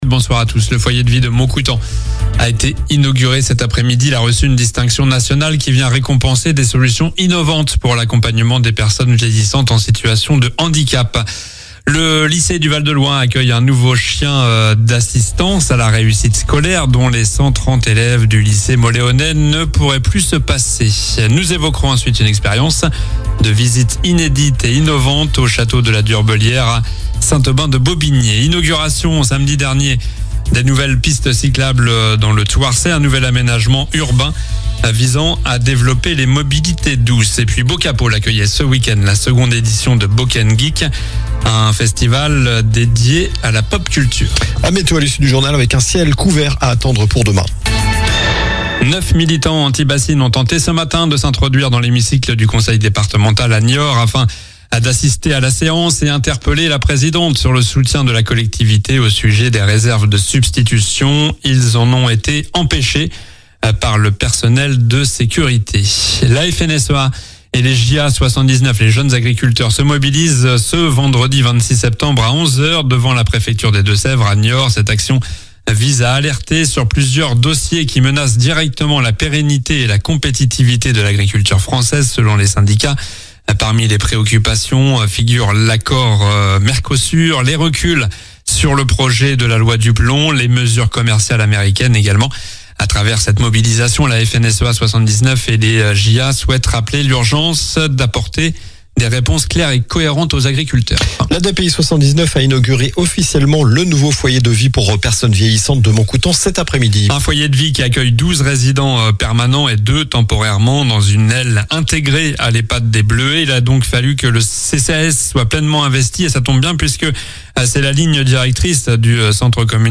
Journal du lundi 22 septembre (soir)